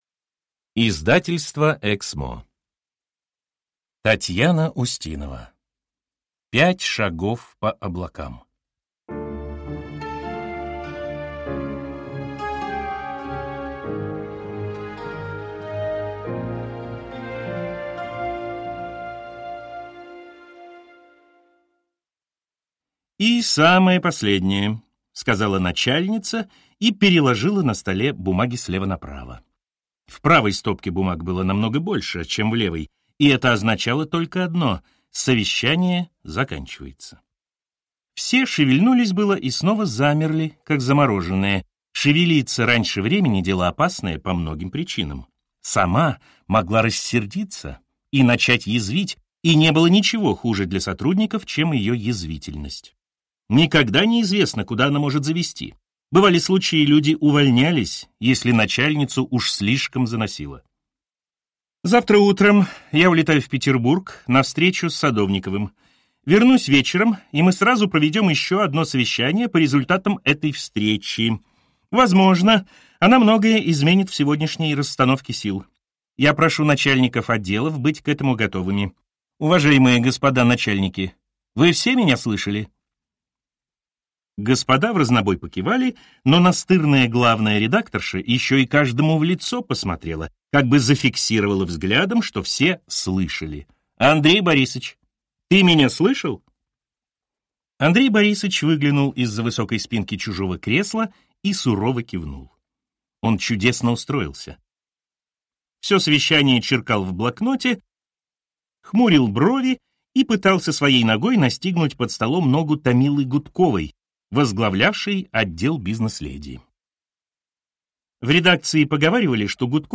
Аудиокнига Пять шагов по облакам | Библиотека аудиокниг
Прослушать и бесплатно скачать фрагмент аудиокниги